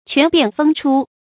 權變鋒出 注音： ㄑㄨㄢˊ ㄅㄧㄢˋ ㄈㄥ ㄔㄨ 讀音讀法： 意思解釋： 隨機應變，鋒芒畢露。